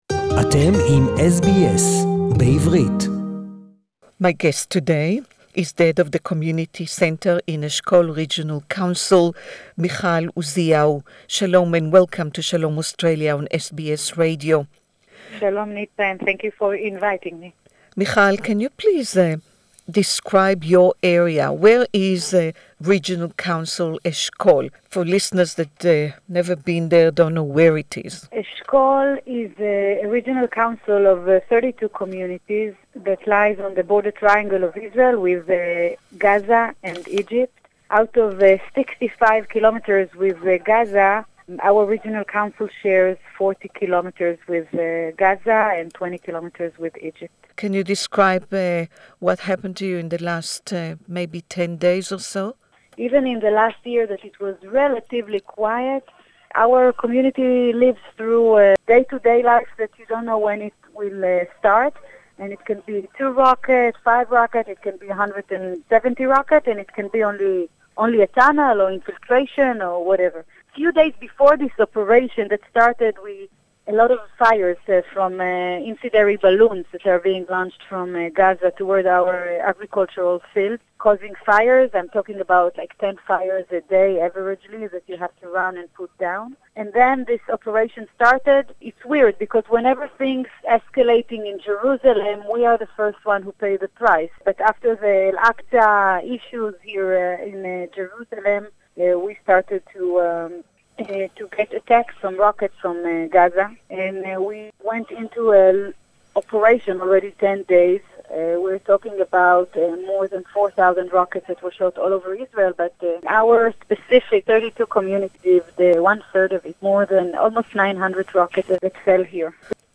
This interview was recorded just hours before the ceasefire was announced...